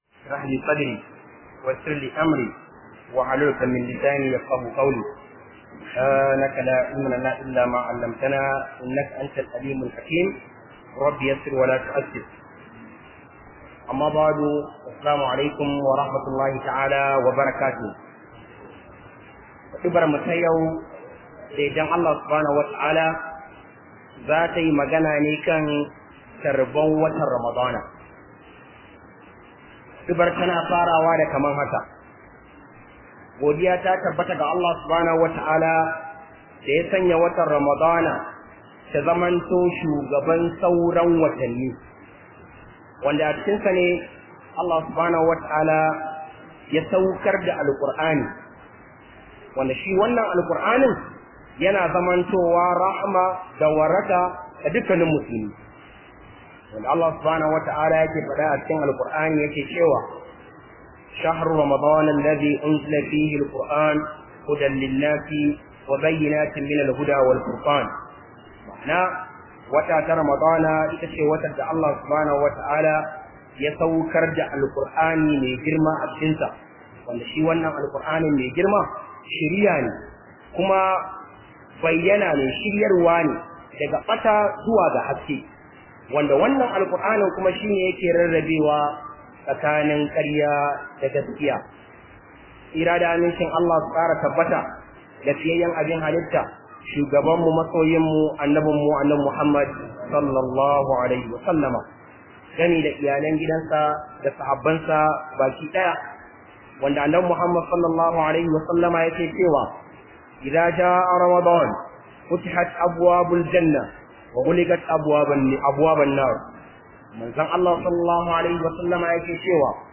033 khudubah kan tarban Ramadan 2019.mp3